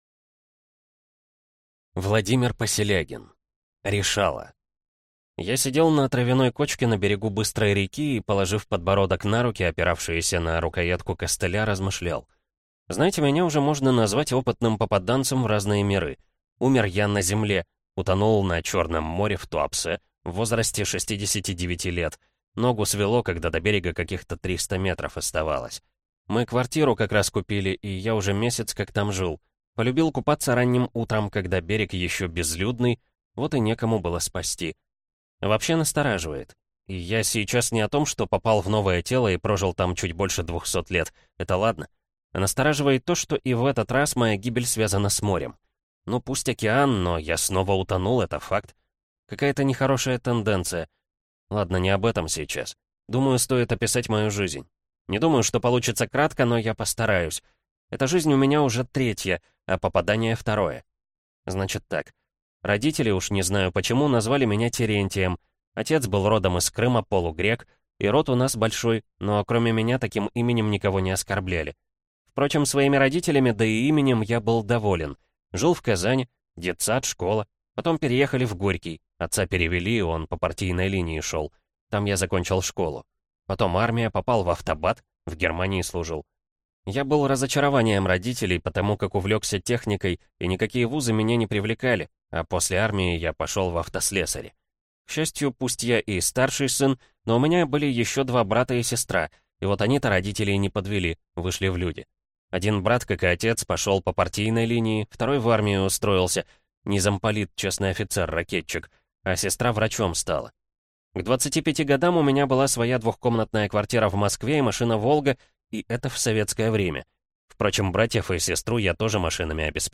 Аудиокнига Решала | Библиотека аудиокниг